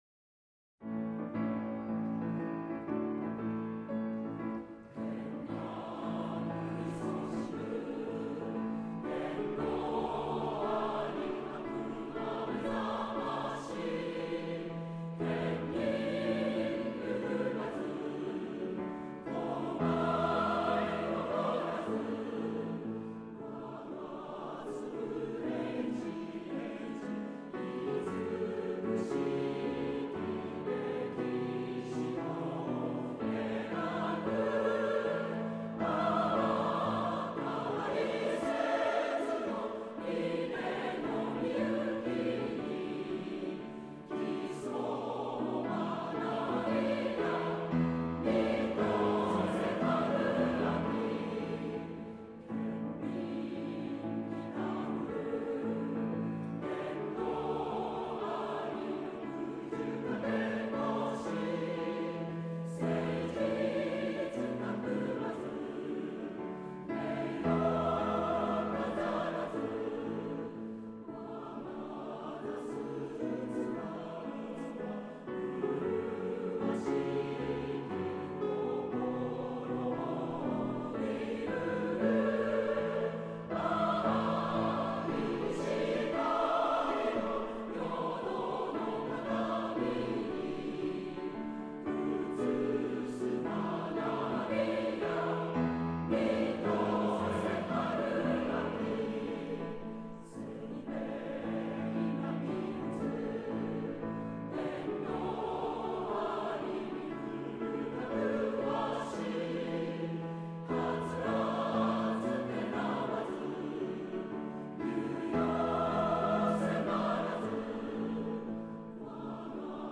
校歌
格調の高い歌詞と軽快なテンポの調べは、ゴールデンコンビの傑作です。
作詞：清水　重道　　作曲：信時　　潔